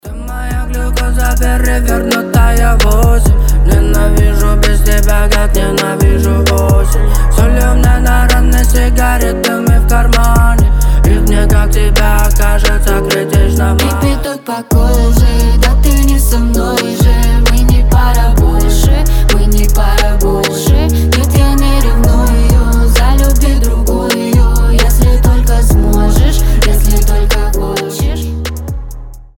• Качество: 320, Stereo
лирика
дуэт